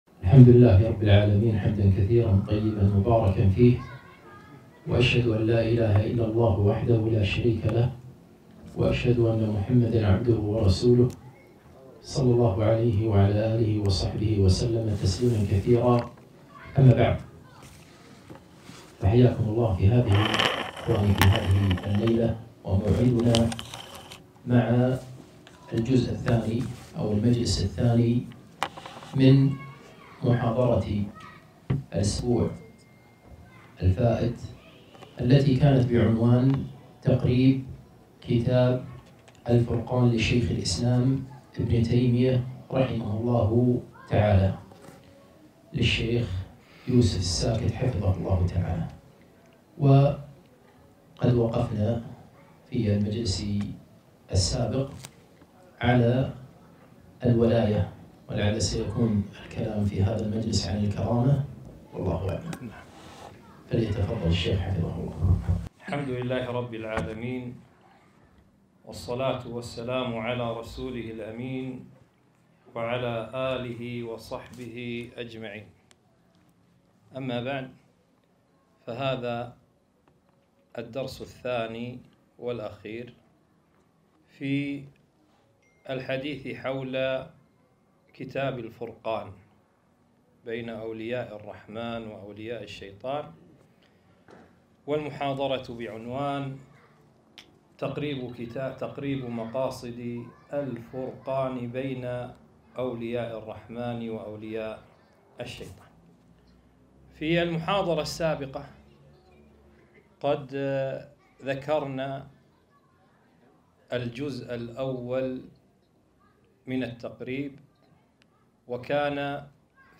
محاضرة - تقريب مقاصد كتاب الفرقان لابن تيمية - الجزء الثاني